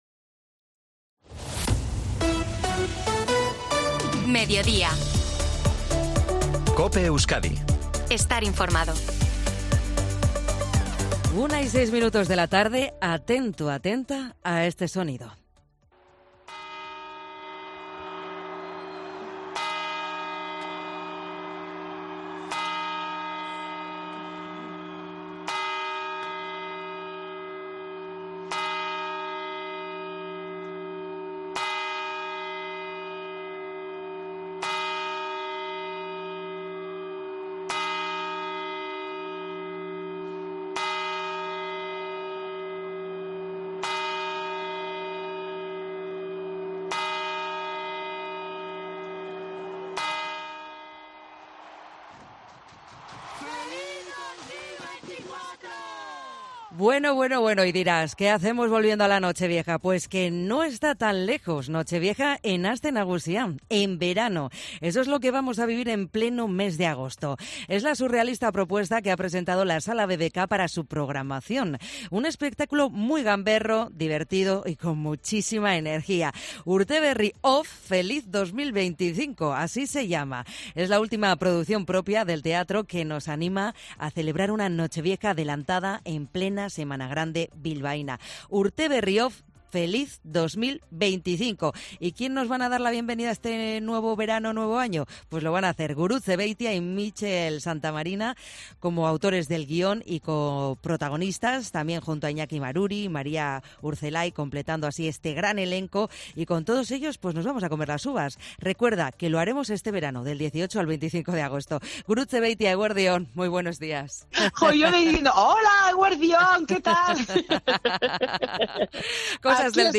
La actriz se pasa por COPE Euskadi para desvelar los detalles de la divertida obra de teatro "Urte Berri off ¡Feliz 2025!", que se verá 18 al 25 de...
Gurutze Beitia, en COPE Euskadi